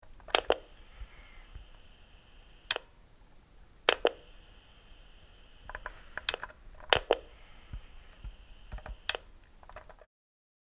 Download Free Technology Sound Effects | Gfx Sounds
Turning-on-and-off-walkie-talkie-multiple-times.mp3